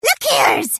Tags: Everquest 2 Ratonga emote Heals me I dont think soes